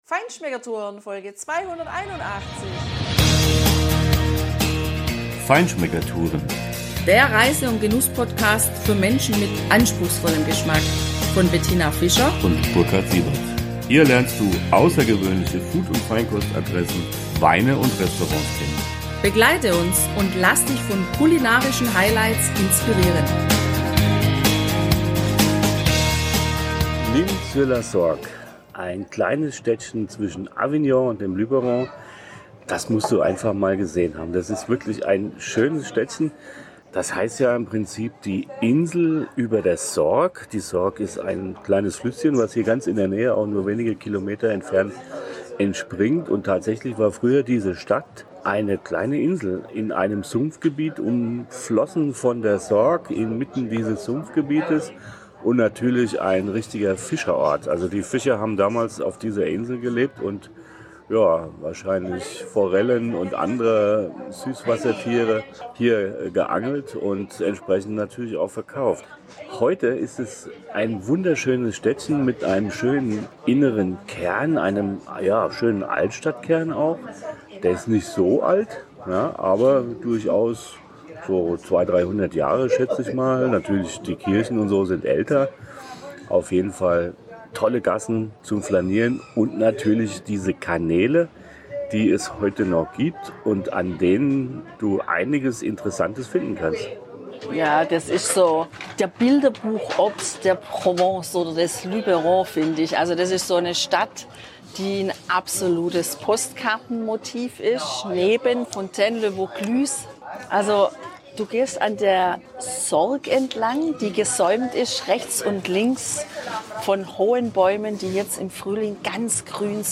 Hör die Gelassenheit auf den Straßen